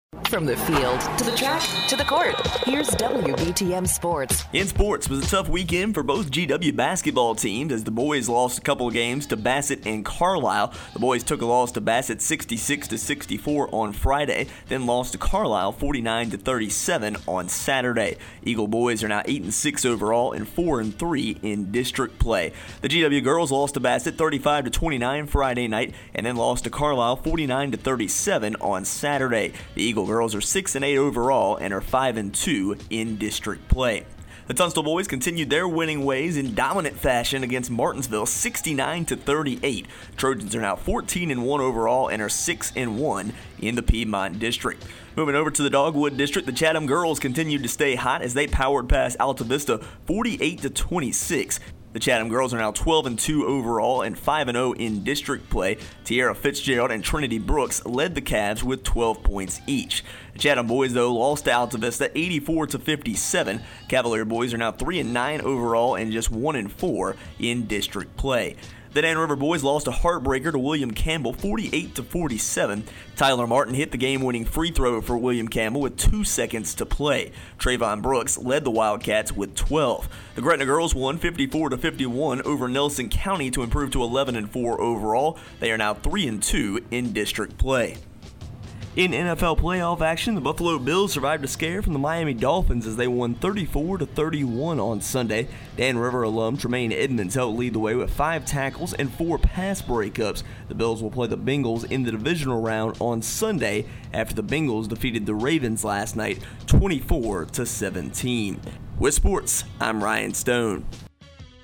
Tunstall Boys Stay Hot, Chatham Girls Win Again and More in our Local Sports Report